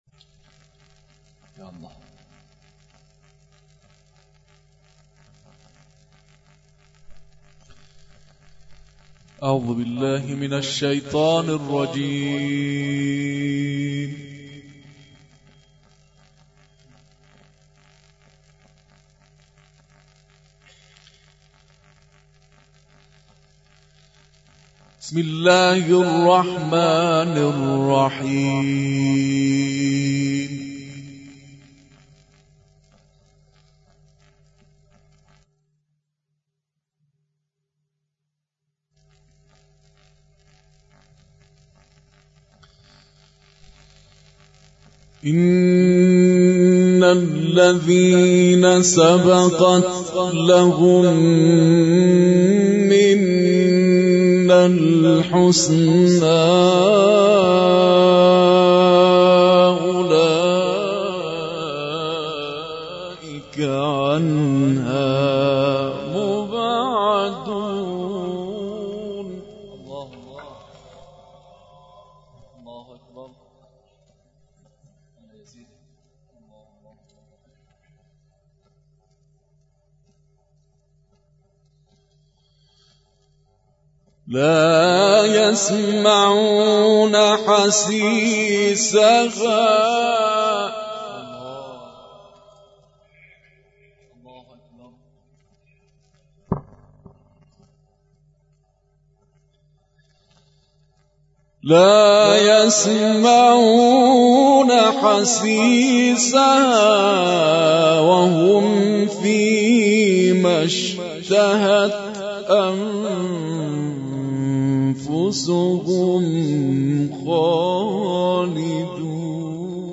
محفل انس با قرآن در مؤسسه سدید تهران برگزار شد + صوت